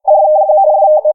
哔哔声扫荡
描述：电子哔哔声
Tag: 复古 向下 向上 电话 DTMF 暂时现象 电子 扫描 音调 寻呼